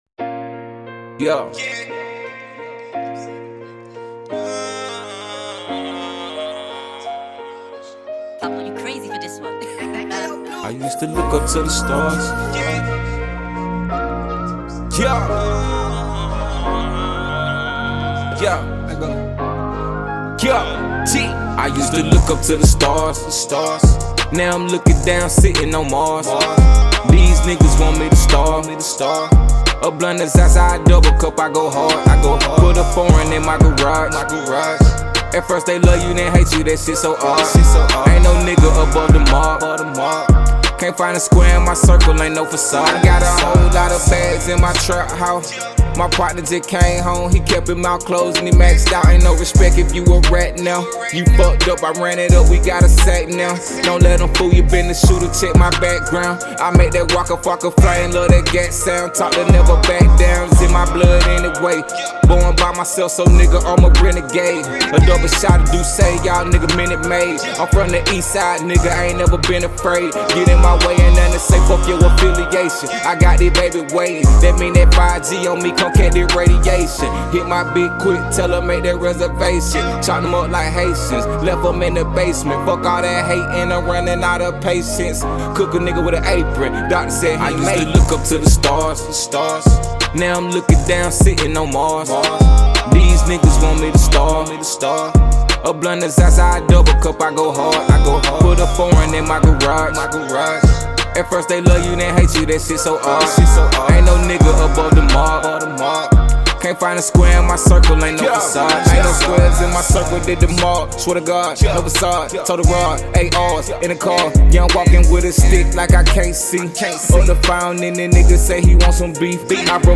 Rap
displays high energy!